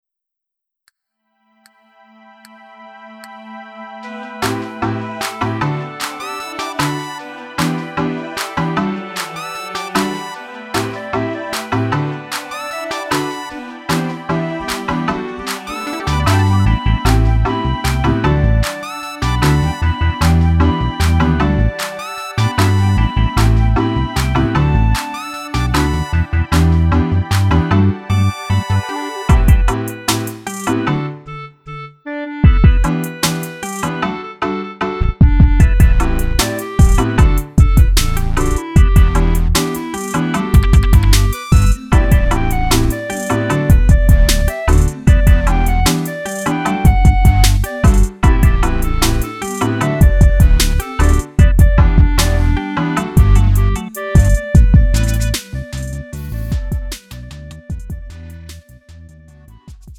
음정 -1키 2:40
장르 가요 구분